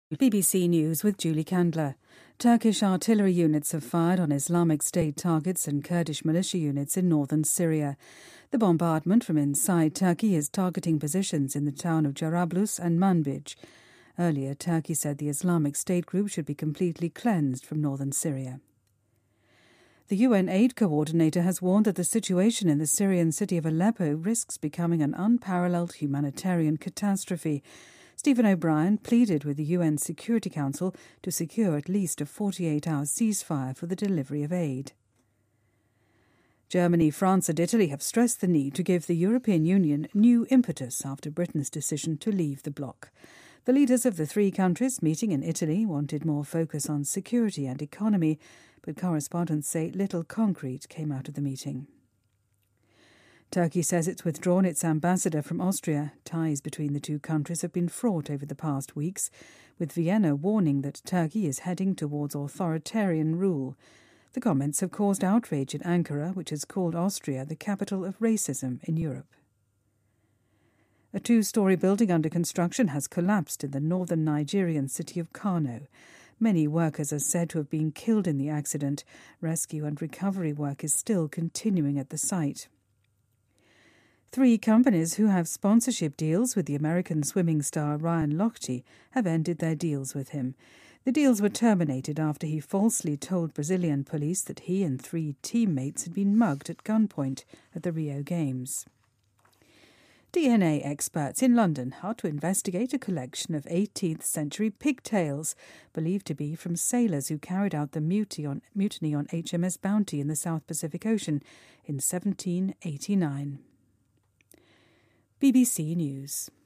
日期:2016-08-25来源:BBC新闻听力 编辑:给力英语BBC频道